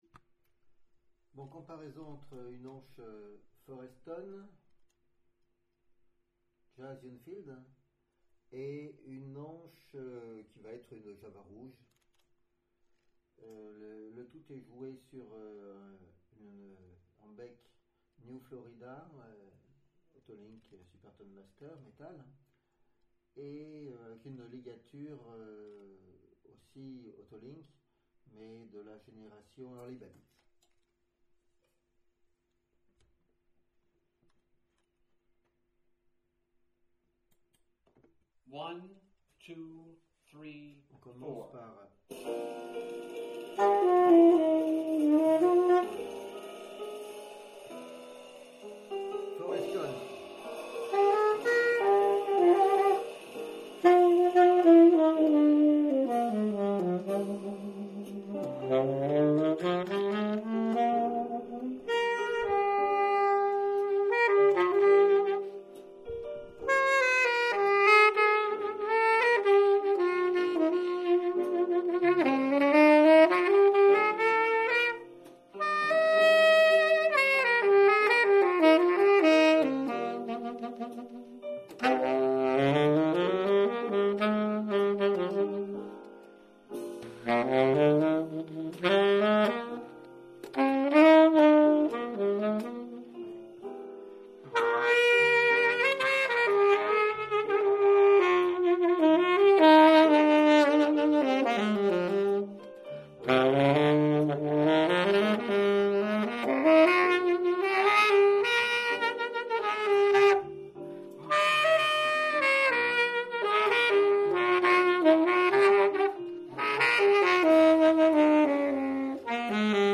All the tenor tunes recorded in Toulouse (France), with a Zoom H4n recorder
2. Tenor mouthpieces and reeds comparisons,
Improvisation 1, (8:16) Vandoren metal V16 T5L vs Otto Link FL "Super tone Master" 6 : mouthpieces contest